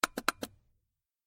Звуки джойстика: Двойной клик